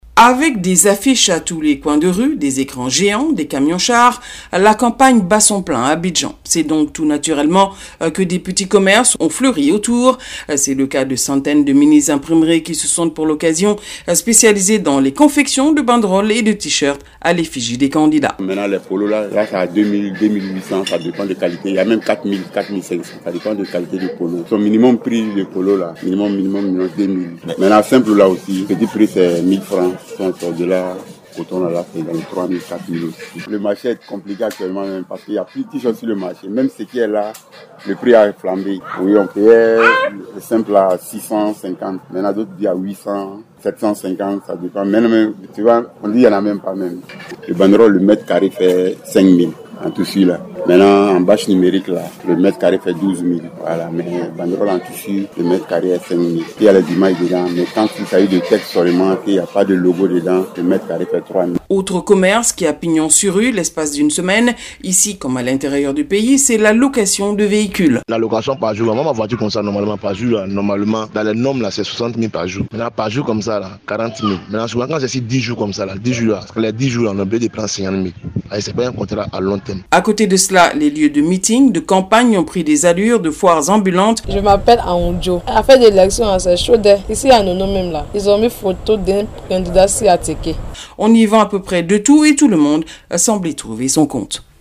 reportage-les-petits-commerces-autour-de-la-campagne-electorale.mp3